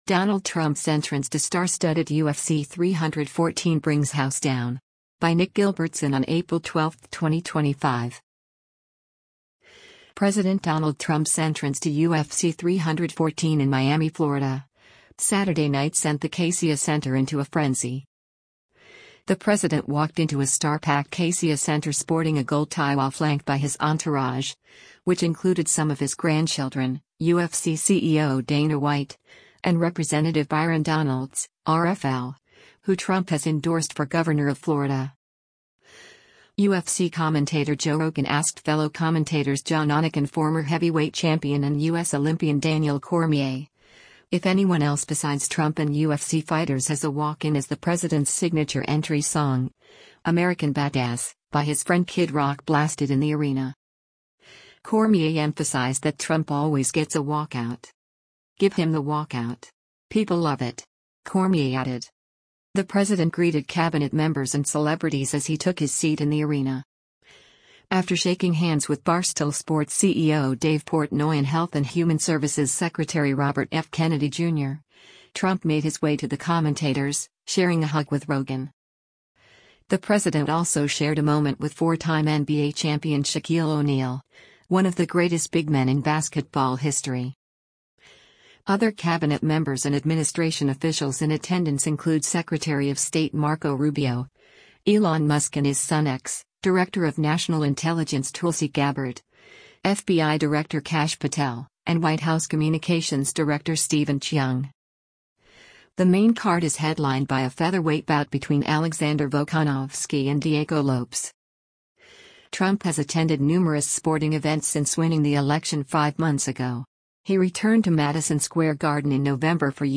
President Donald Trump’s entrance to UFC 314 in Miami, Florida, Saturday night sent the Kaseya Center into a frenzy.
UFC Commentator Joe Rogan asked fellow commentators John Anik and former Heavyweight Champion and U.S. Olympian Daniel Cormier, if anyone else besides Trump and UFC fighters has a “walk-in” as the president’s signature entry song, “American Badass,” by his friend Kid Rock blasted in the arena.
“Give him the walkout! People love it!” Cormier added.